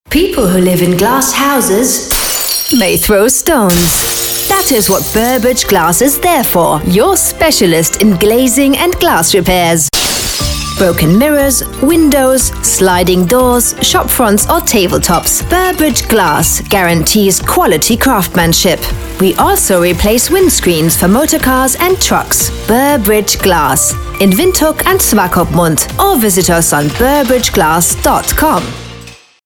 Ich bin Profi-Sprecherin und spreche für Sie Werbung, Audio Ads, Dokumentation, E-Learning, Zeichentrick, Jingles, Spiele.
Sprechprobe: Sonstiges (Muttersprache):